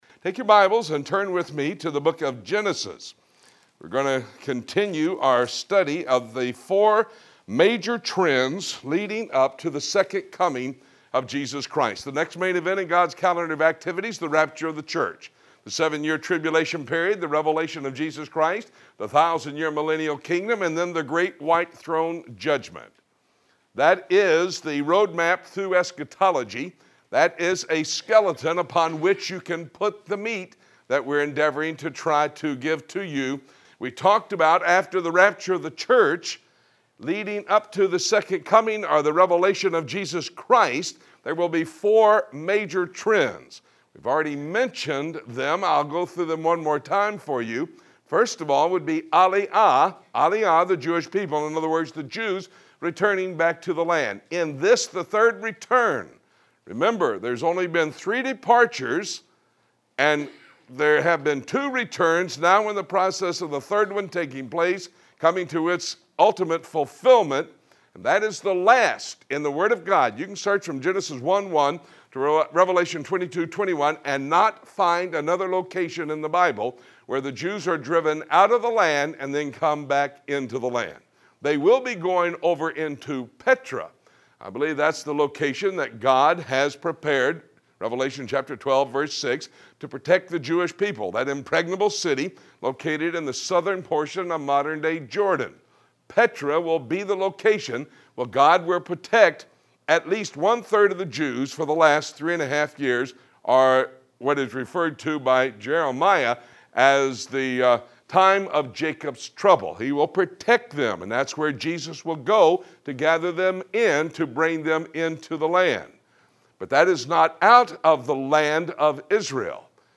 Listen to the audio of this lesson